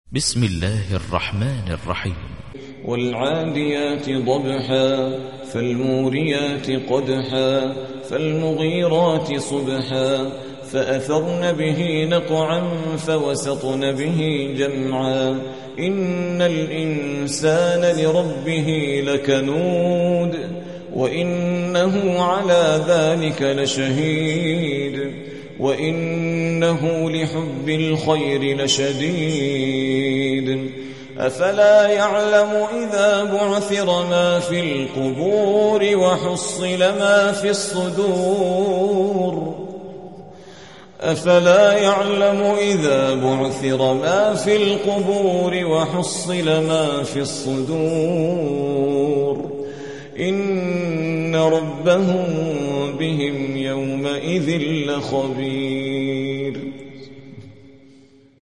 100. سورة العاديات / القارئ